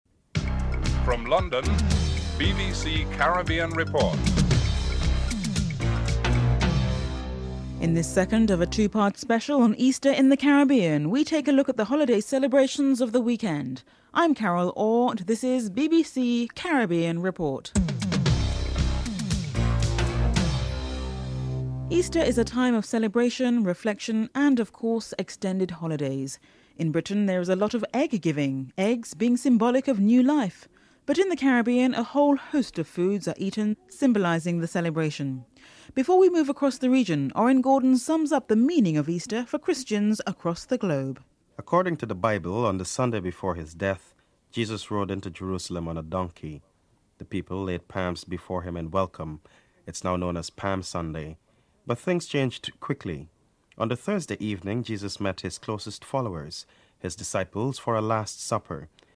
Archbishop Edgerton Clarke is interviewed (02:05-04:22)en_US